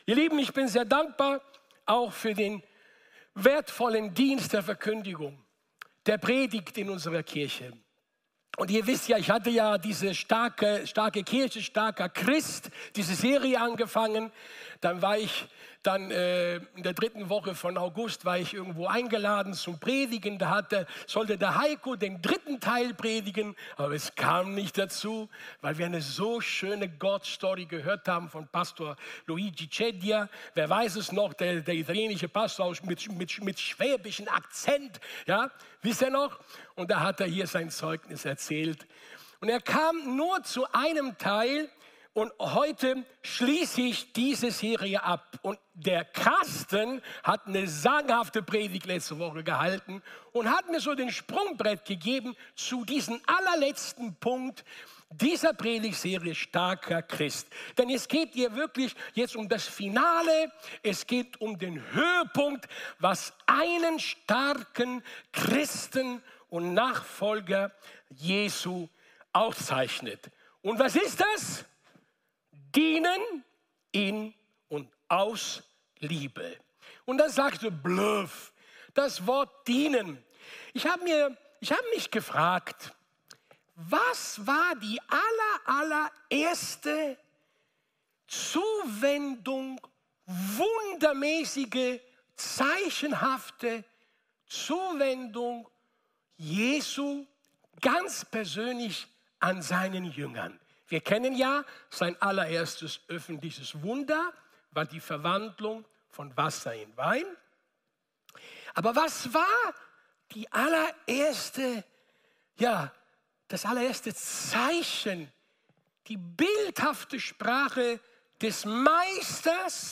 Dienstart: Sonntag
14.09.-Live-Gottesdienst-Alive-Church-short.mp3